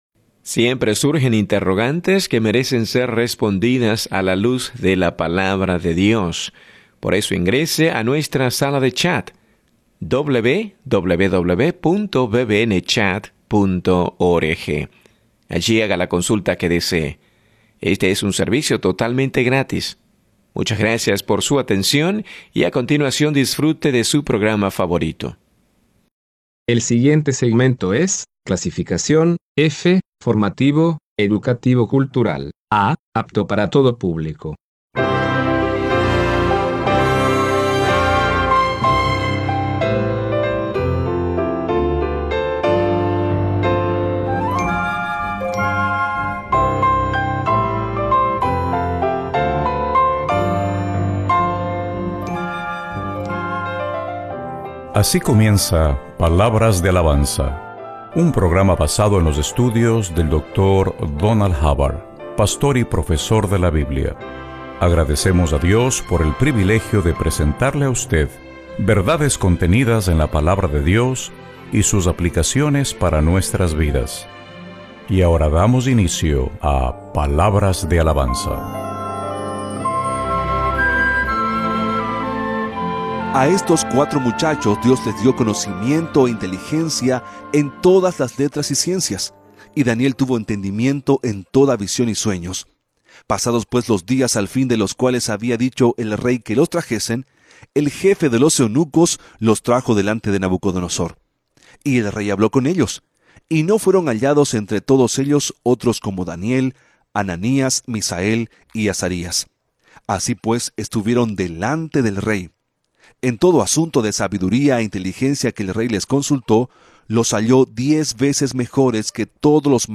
Este programa que sale al aire de lunes a viernes es una enseñanza expositiva de la Biblia.